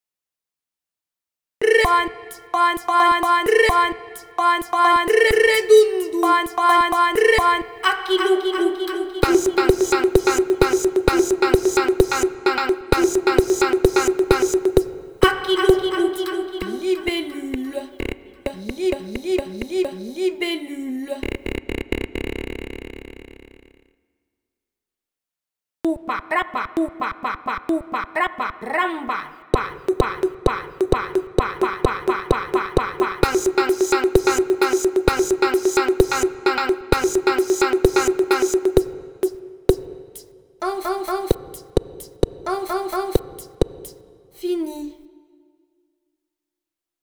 Cançon de la classa de Galhac sus l'Espelida
a partir dels mots de l'espelida cantats